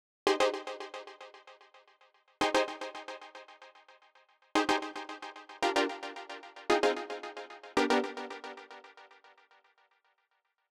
06 Chord Synth PT4+tail.wav